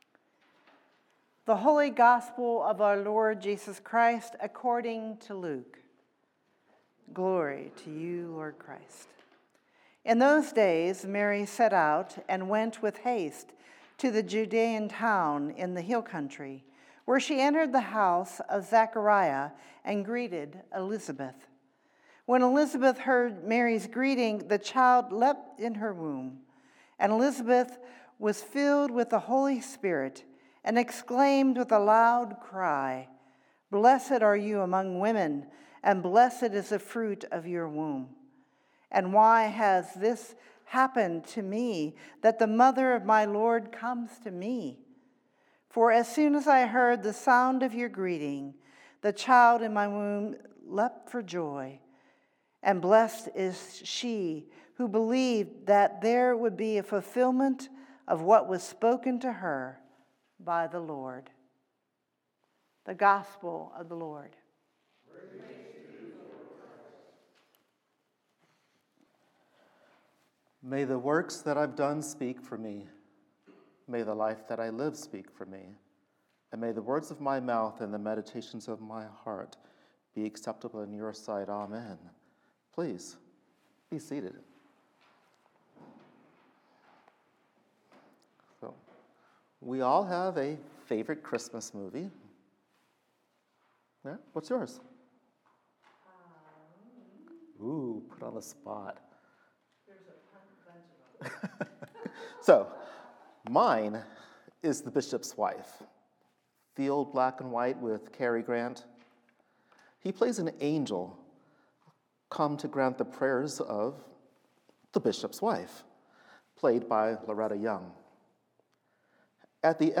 Fourth Sunday in Advent, Luke 1:39-45 (Taize service)